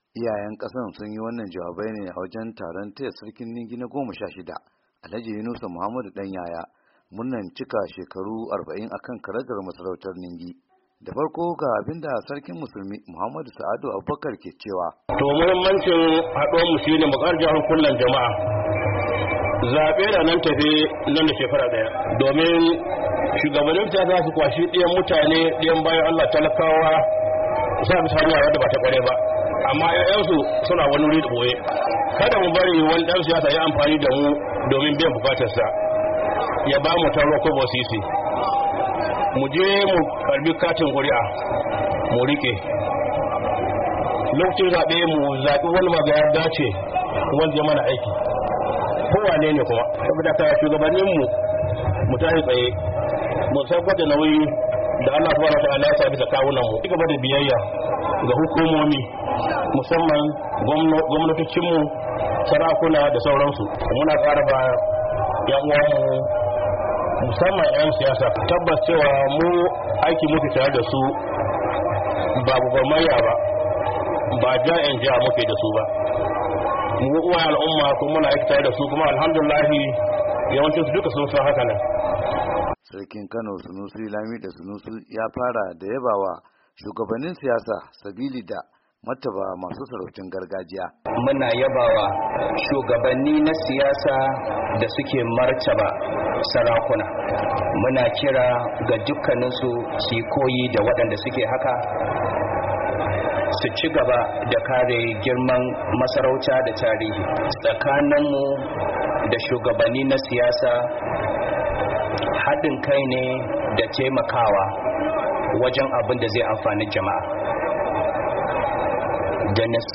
Sarakunan dai sun hadu ne a wajen taron taya sarkin Ningi na 16, Alhaji Yunusa Danyaya, murnar cika shekaru 40 kan karagar masarautar Ningi.
Yayin wata hira da ya yi da Muryar Amurka, uban taro mai martaba Alhaji Muhammadu Yunusa Dan Yaya, ya yi tsokaci ne kan matsayar masarautun gargaji a tsarin mulkin kasa.